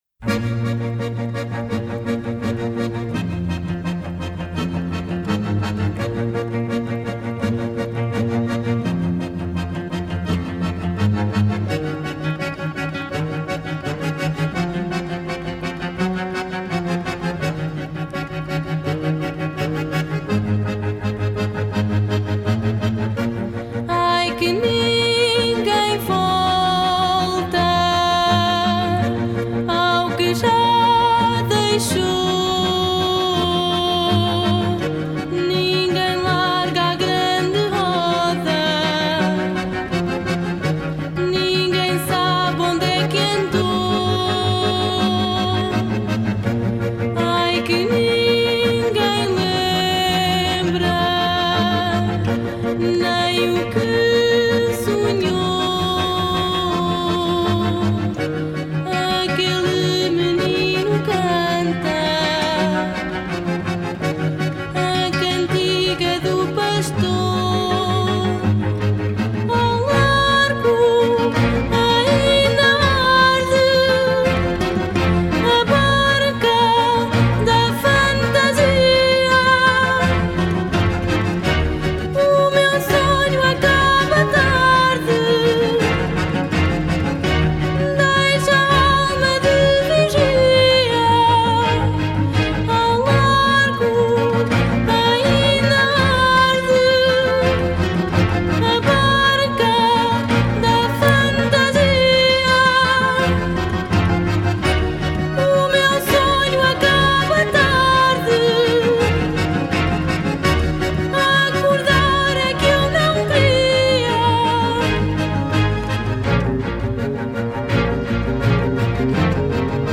如同天使般的声音，